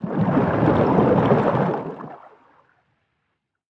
Sfx_creature_arcticray_swim_slow_01.ogg